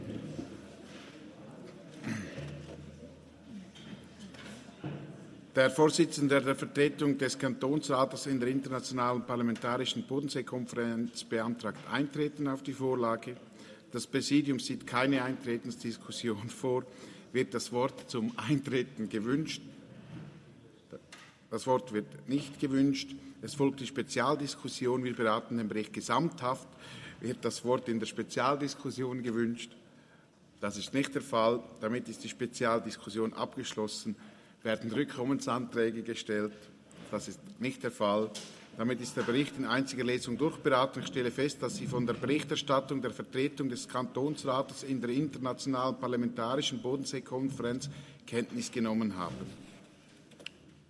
Jäger-Vilters-Wangs, Ratspräsident, stellt Eintreten auf die Vorlage fest.
Session des Kantonsrates vom 19. bis 21. September 2022